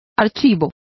Complete with pronunciation of the translation of registry.